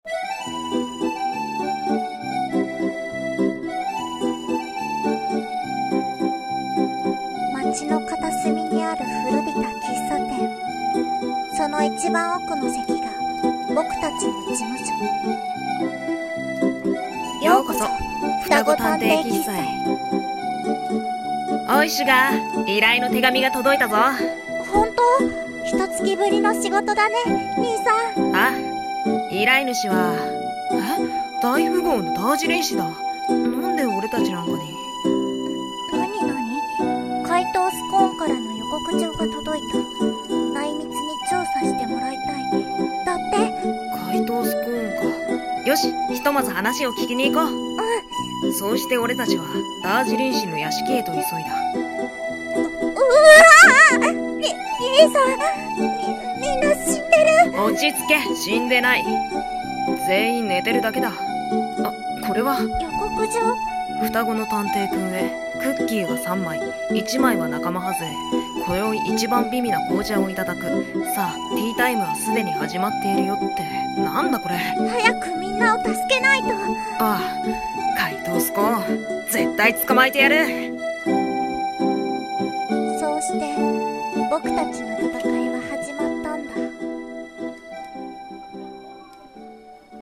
【声劇】双子探偵喫茶【台本】